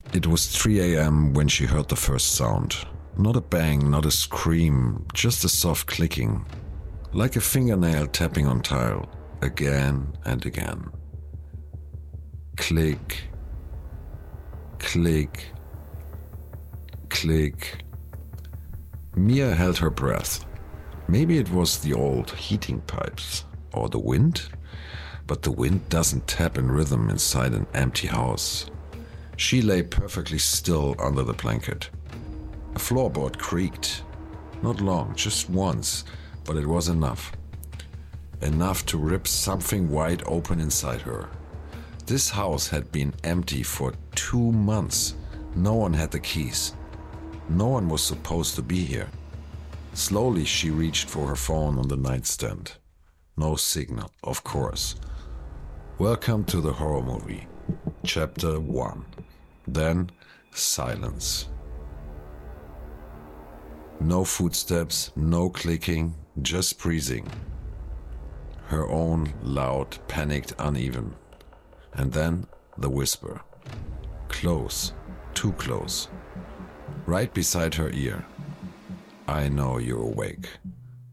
Male
I work both, in German and in English with accent. My voice ranges from warm and deep to calm and engaging, making it appropriate for a variety of projects.
Audiobooks
English Audiobook 1